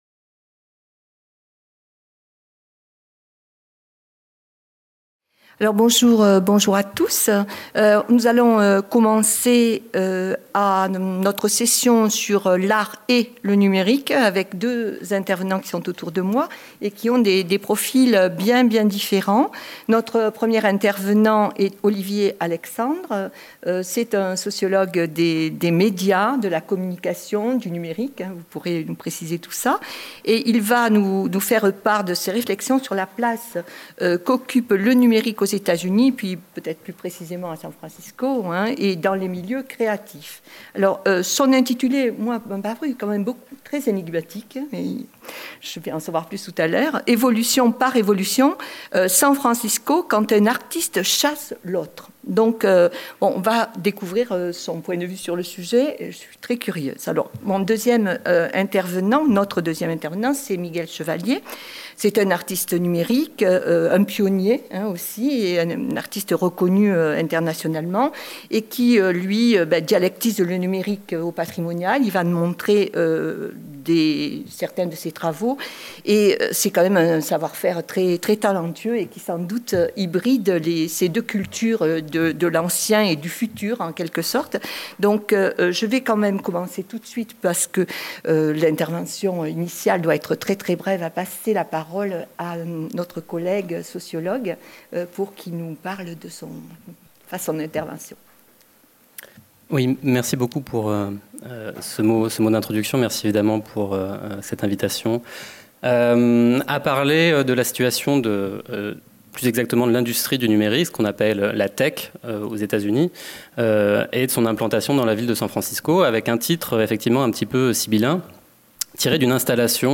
Vendredi 20 mai - Philharmonie, Salle de conférence 14H15 L’art et le numérique